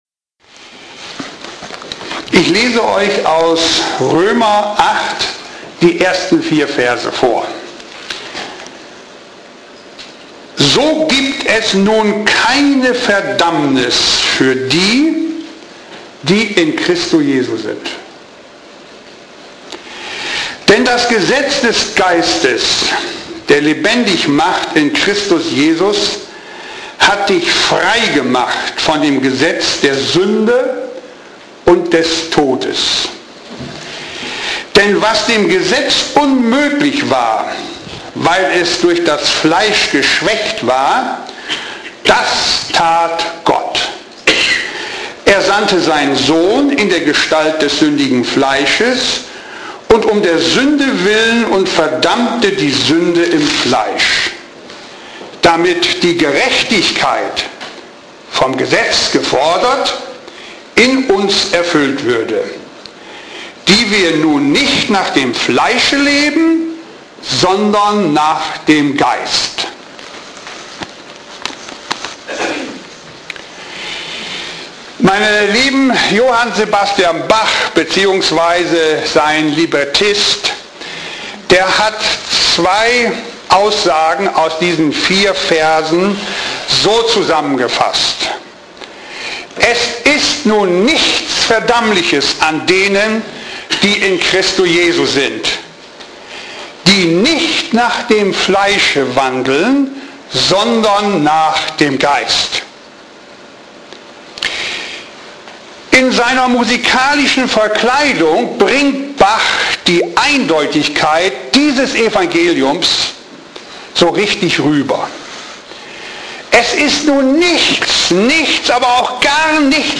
Keine Verdammnis | Marburger Predigten